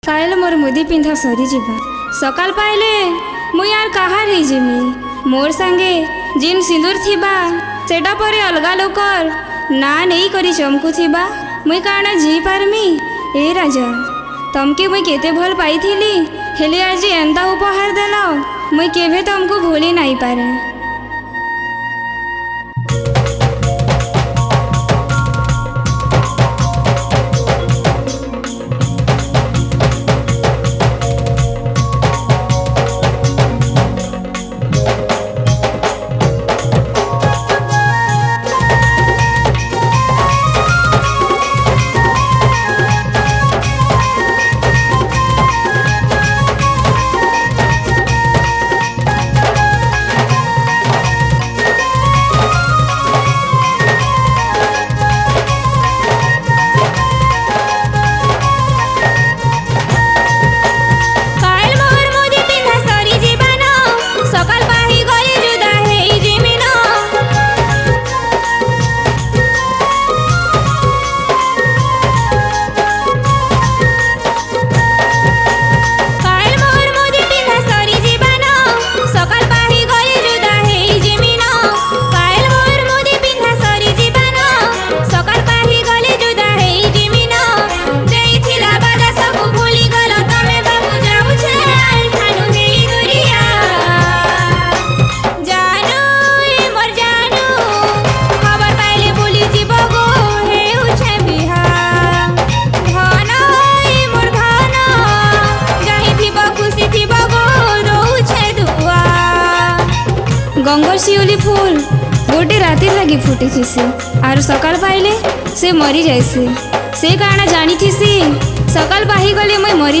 Category: New Sambalpuri Folk Song 2021